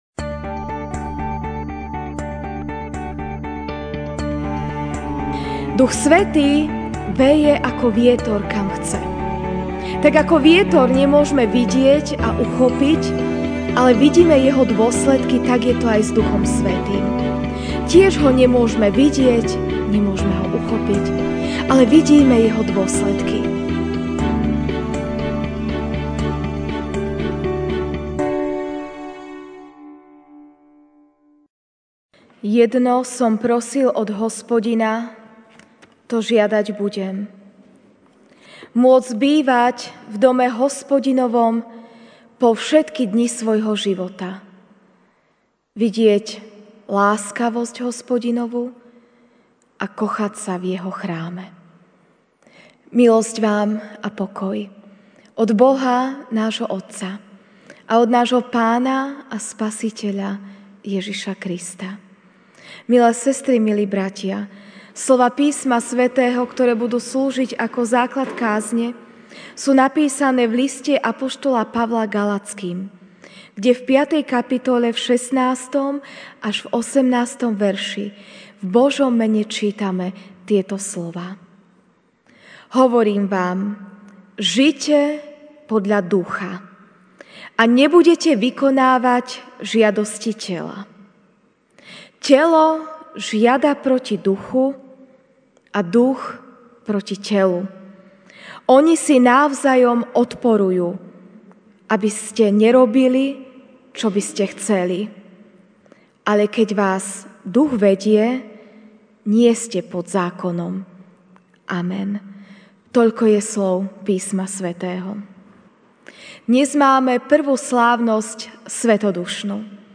jún 09, 2019 Pôsobenie Ducha Svätého MP3 SUBSCRIBE on iTunes(Podcast) Notes Sermons in this Series Večerná kázeň: Pôsobenie Ducha Svätého (G 5, 16-18) Hovorím však: Žite podľa Ducha a nebudete vykonávať žiadosti tela.